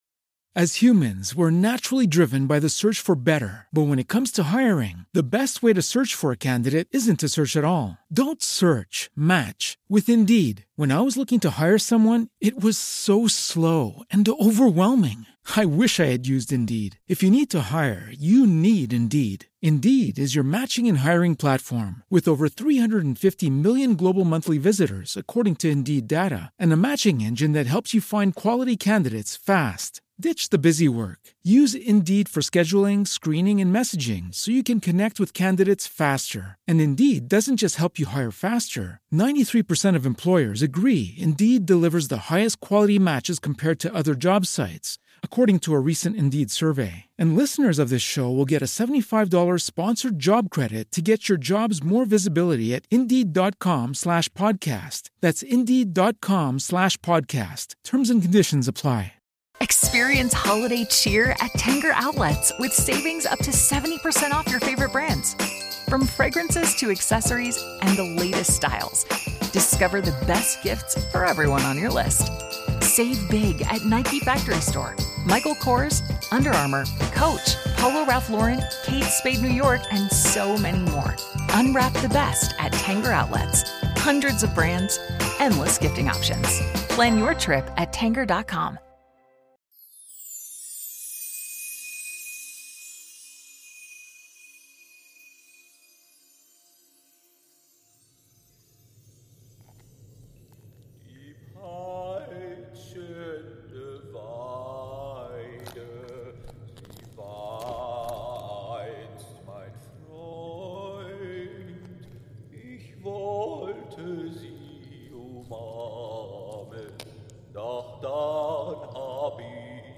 18. Türchen | Eine unerwartete Ginny - Eberkopf Adventskalender ~ Geschichten aus dem Eberkopf - Ein Harry Potter Hörspiel-Podcast Podcast